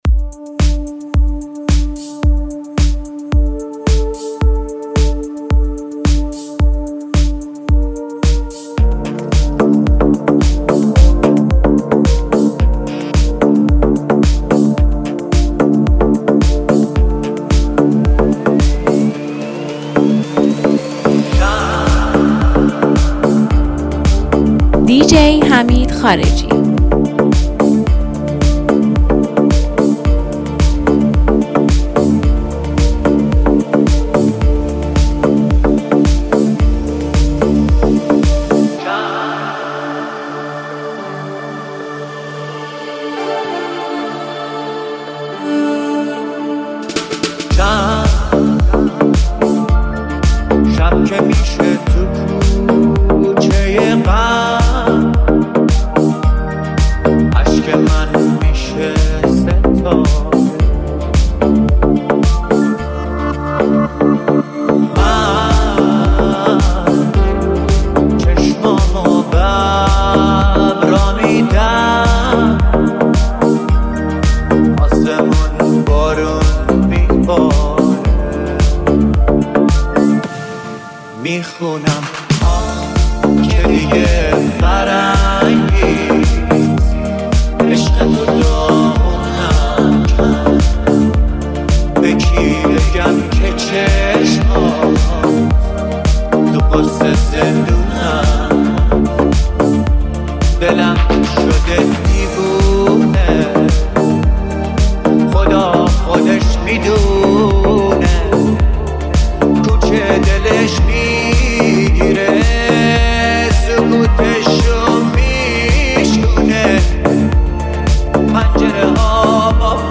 میکس رویایی و نوستالژی